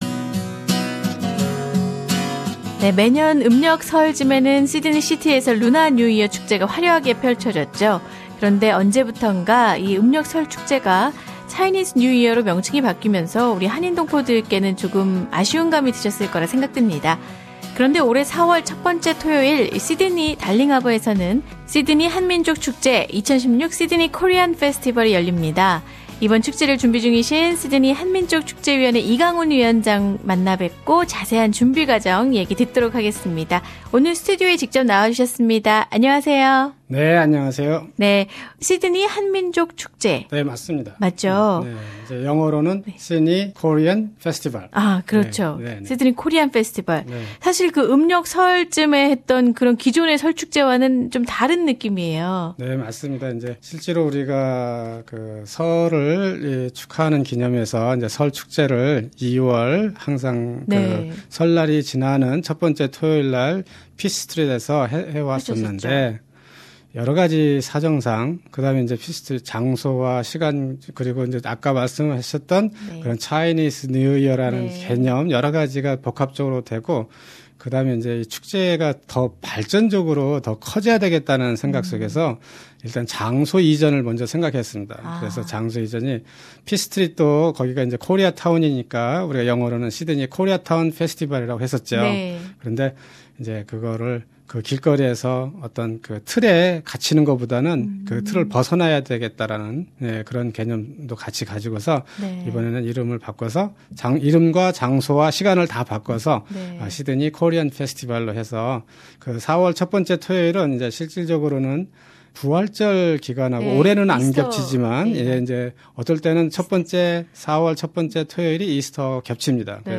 [Interview]